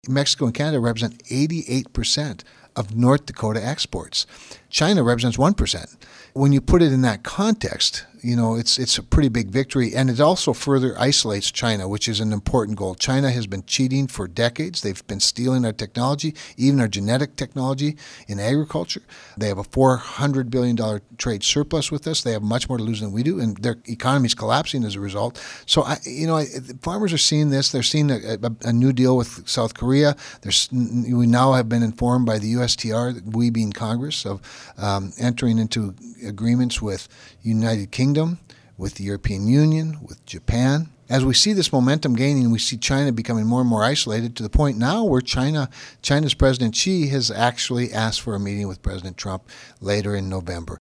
Cramer said there’s still anxiety in farm country concerning commodity price especially soybeans.